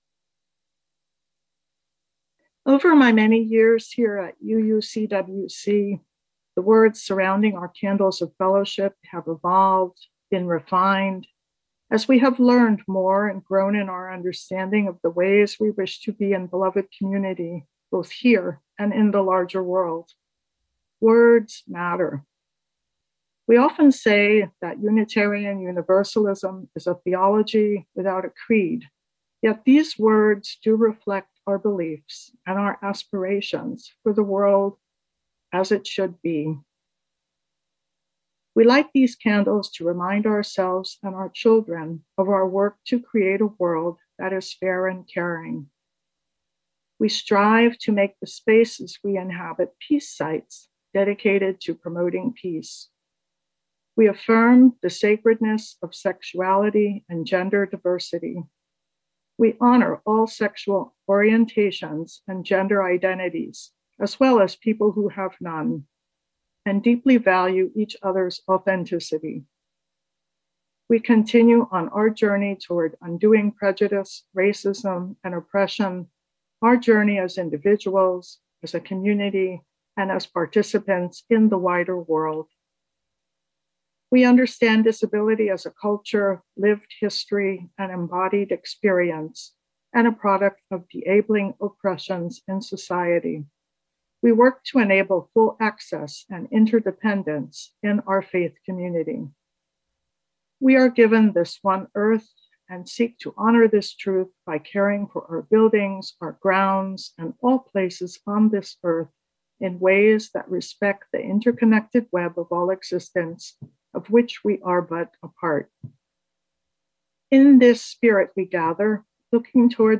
Sunday-Service-April-25-2021-Final